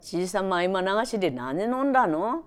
Aizu Dialect Database
Type: Single wh-question
Final intonation: Rising
WhP Intonation: Rising
Location: Showamura/昭和村
Sex: Female